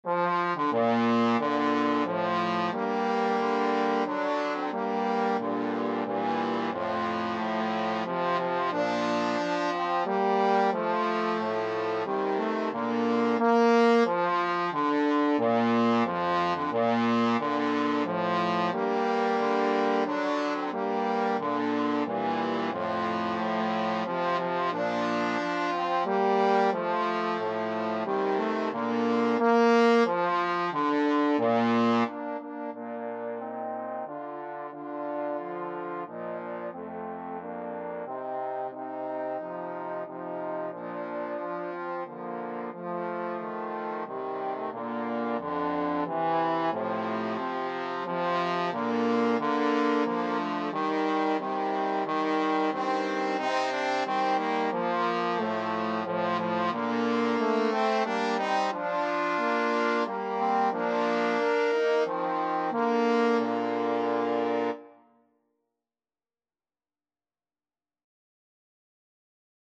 3/4 (View more 3/4 Music)
Bb major (Sounding Pitch) (View more Bb major Music for Trombone Trio )
Maestoso = c.90
Trombone Trio  (View more Intermediate Trombone Trio Music)
Traditional (View more Traditional Trombone Trio Music)